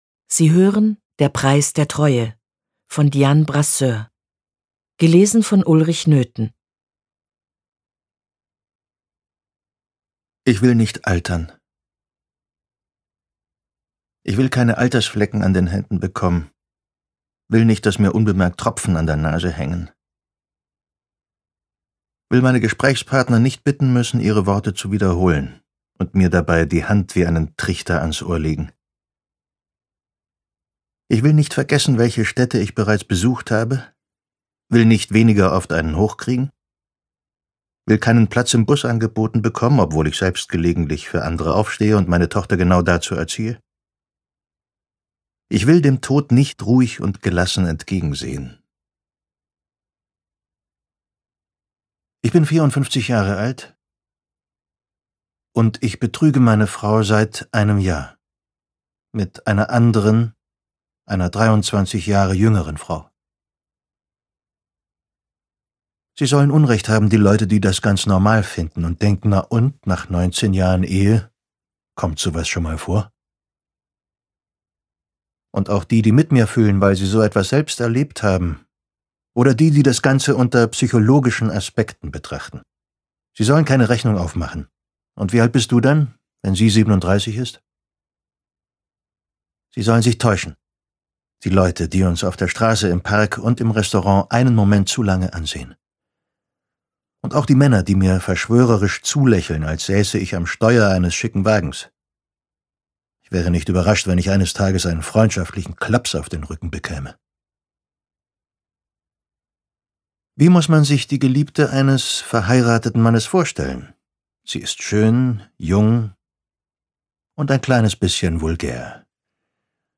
Ungekürzte Lesung mit Ulrich Noethen (3 CDs)
Ulrich Noethen (Sprecher)